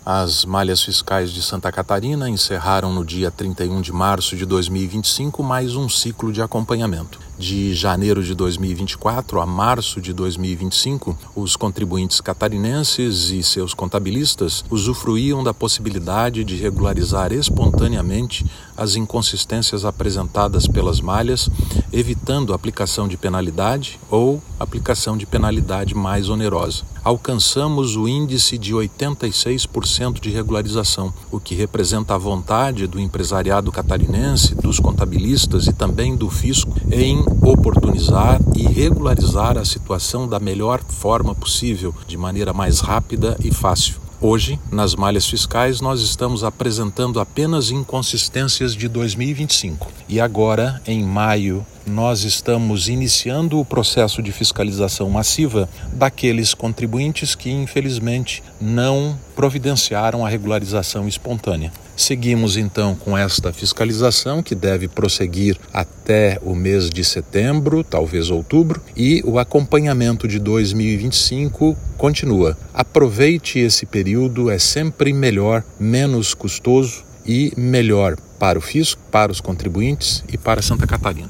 SECOM-Sonora-auditor-fiscal-da-Receita-Estadual.mp3